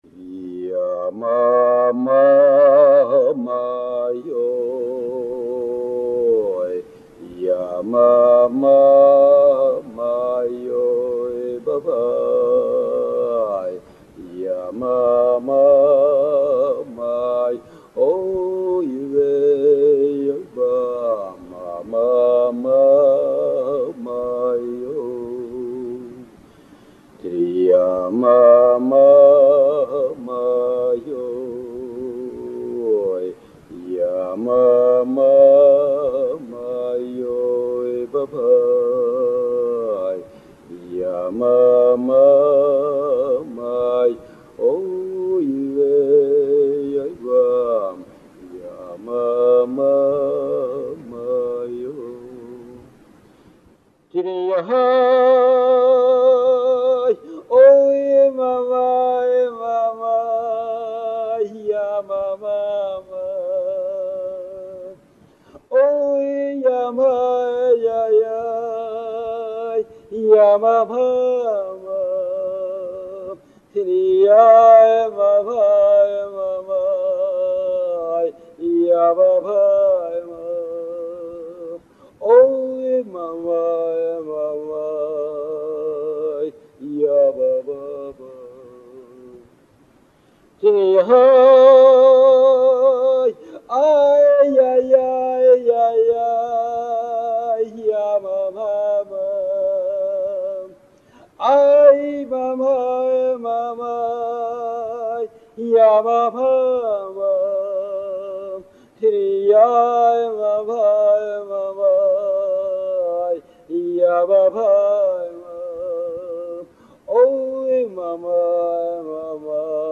ניגון זה הוא ניגון רע"א בספר הניגונים והוא ללא מילים.